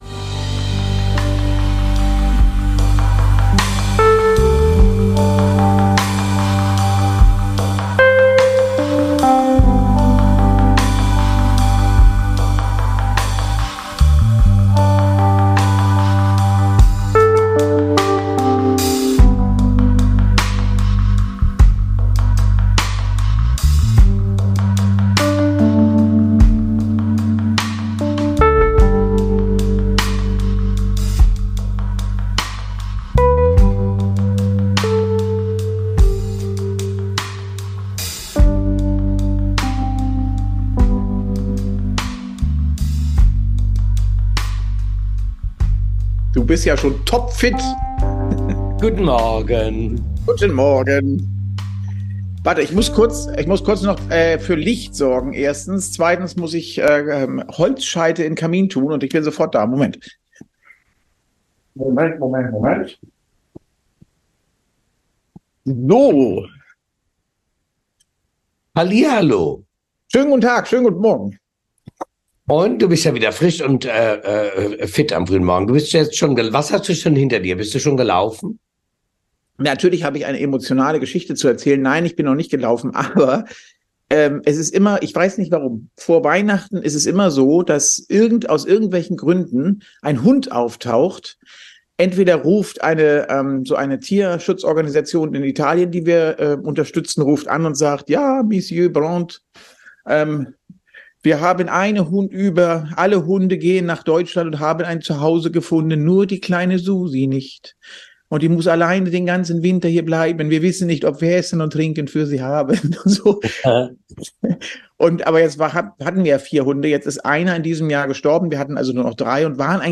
Ein entspanntes Gespräch. Aufgezeichnet im wunderbaren Hotel Zumnorde in Erfurt.